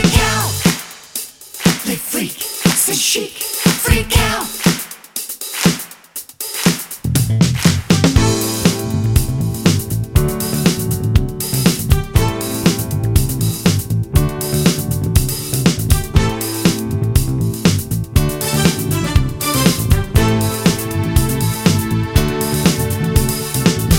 No Guitars Or Backing Vocals Disco 3:30 Buy £1.50